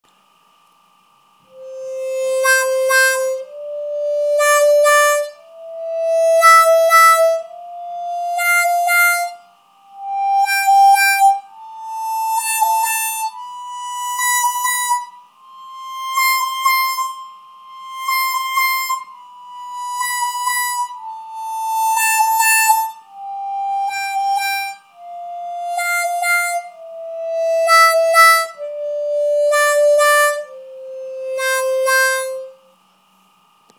Сыграть гамму «до мажор» +4-4+5-5+6-6-7+7 и обратно +7-7-6+6-5+5-4+4, на каждой ноте делая по два эффекта вау-вау.
Dvojnoj-vau-vau.mp3